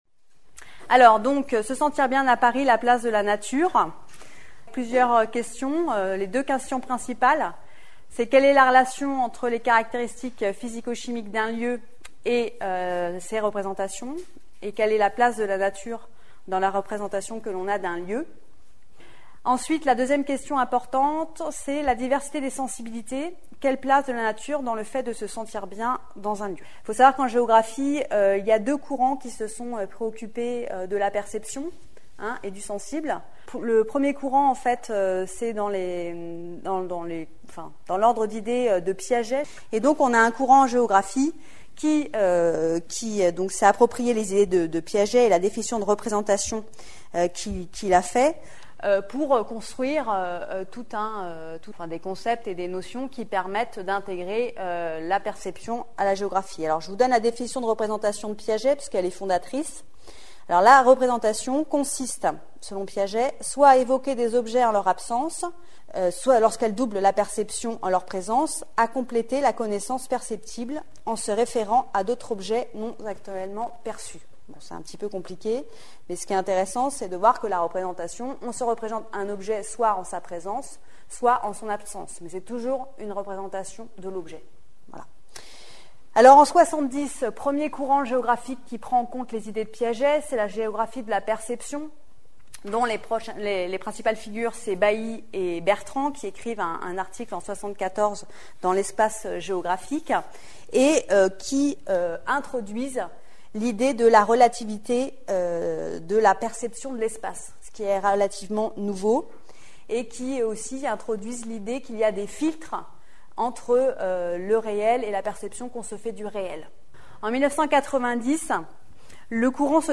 Son exposé s'appuie sur une étude géographique en dialogue avec les neurosciences menée dans trois micro-quartiers parisiens.